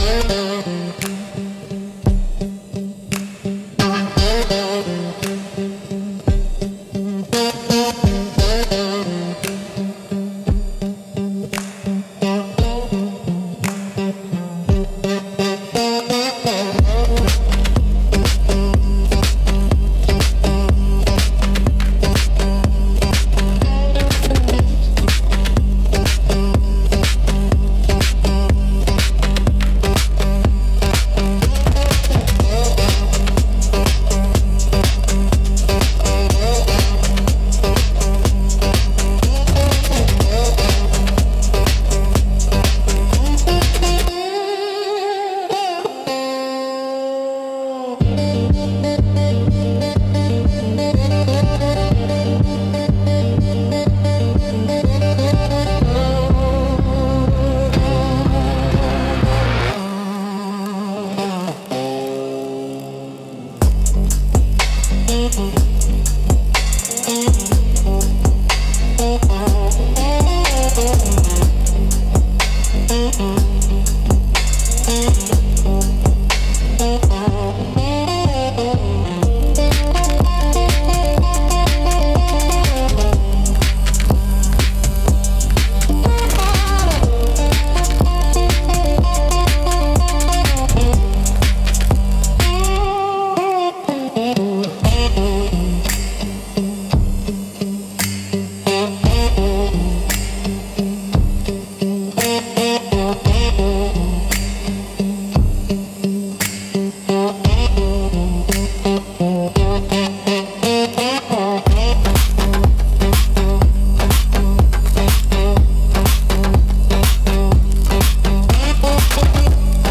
Instrumental - 2.24 Cathedral Collapse